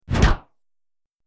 دانلود آهنگ تصادف 29 از افکت صوتی حمل و نقل
جلوه های صوتی
دانلود صدای تصادف 29 از ساعد نیوز با لینک مستقیم و کیفیت بالا